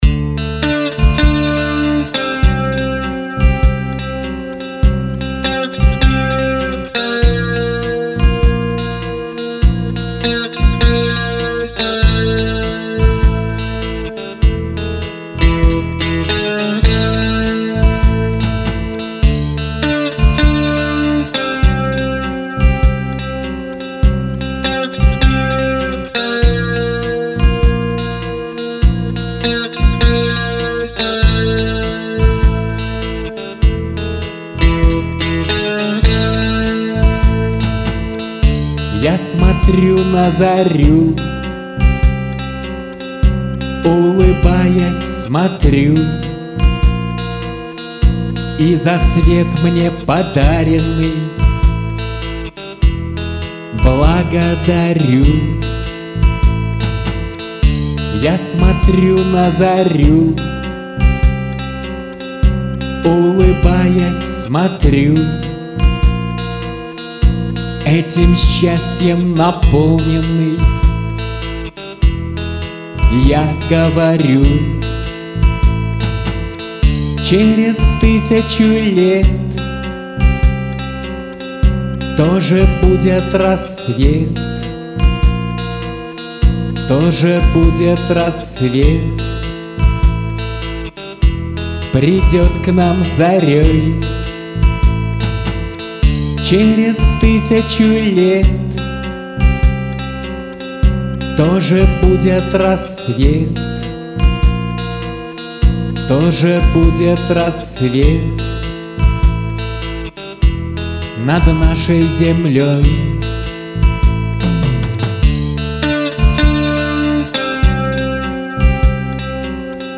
(2018, Рок)